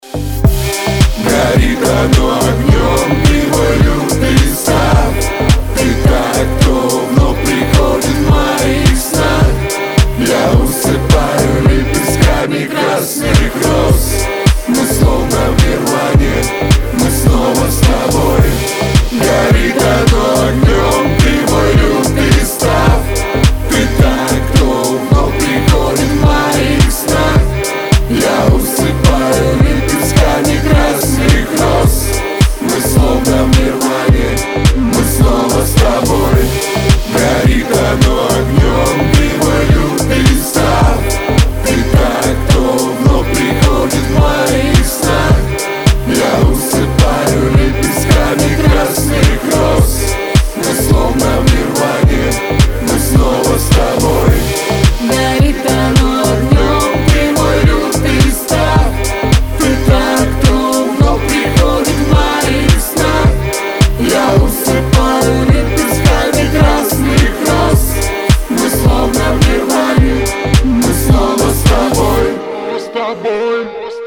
• Качество: 320, Stereo
лирика
русский рэп
дуэт